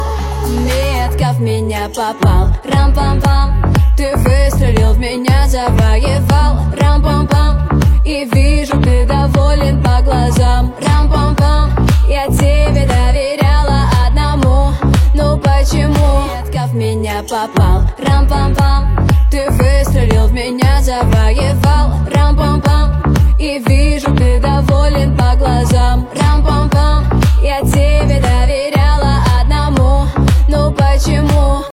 • Качество: 320, Stereo
поп
Cover
красивый женский голос